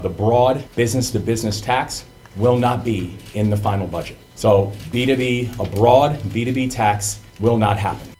Maryland businesses were able to breathe a small sigh of relief when it was announced this week that the 2.5% services tax was off the table in Annapolis. Governor Moore made the announcement at a press gathering…